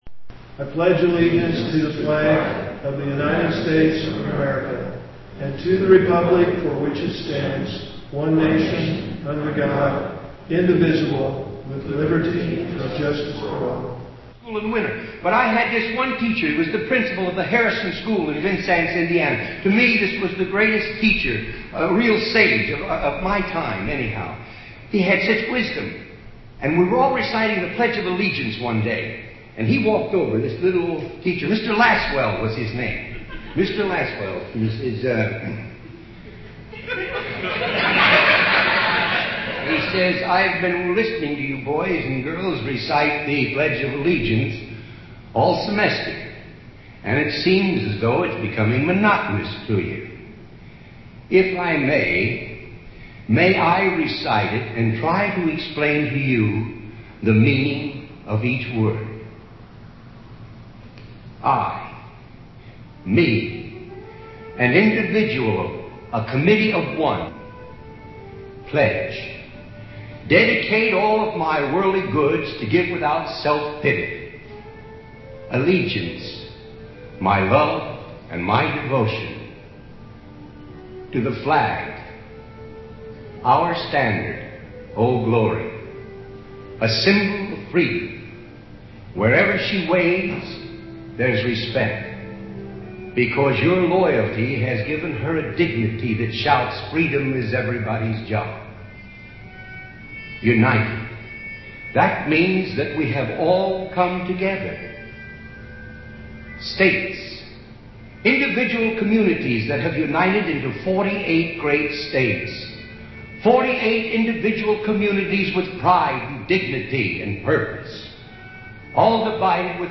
violin solo
recitations
violin and guitar
piano and background narrative